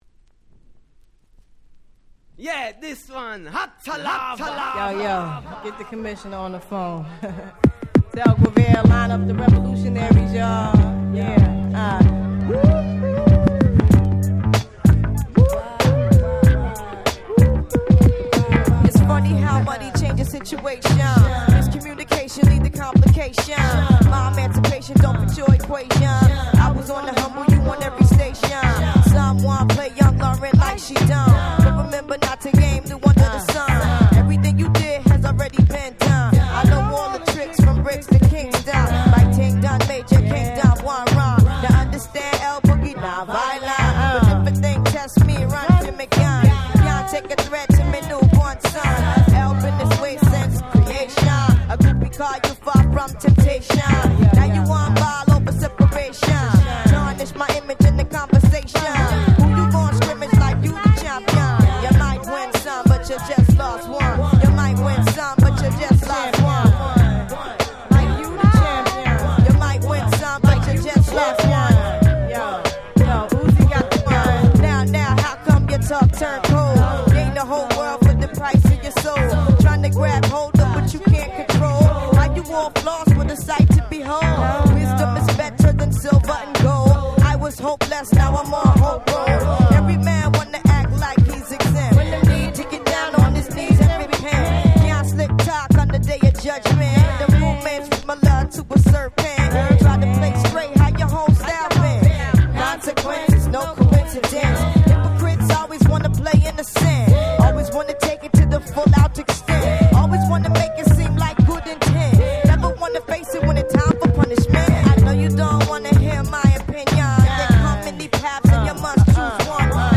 99' Super Hit R&B !!
オリジナルよりもNeo Soulに寄せた好Remixです！